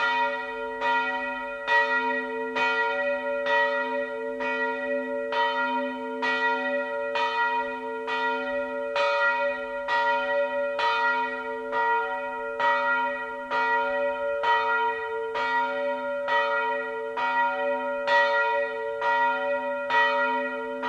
Wendelin-Glocke
Wendelinsglocke.mp3